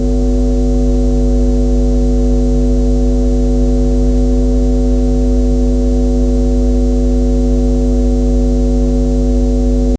soft-electric-hum-like-a-knj4dyfc.wav